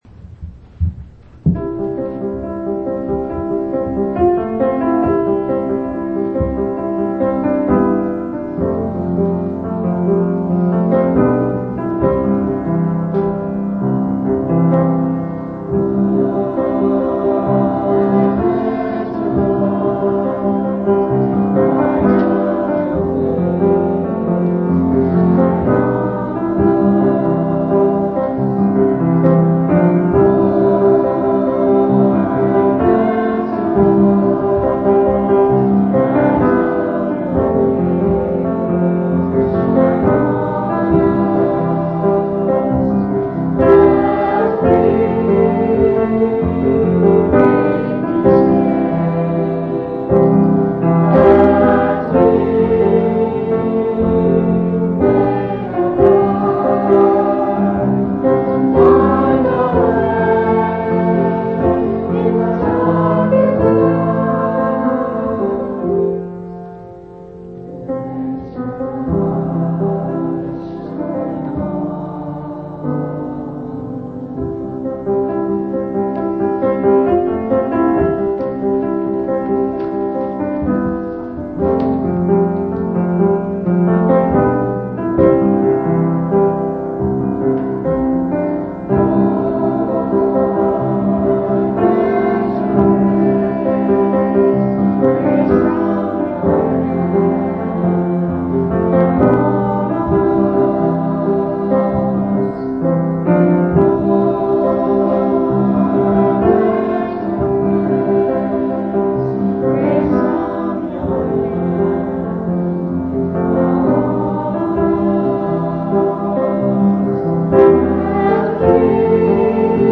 8/8/1997 Location: Missouri Reunion Event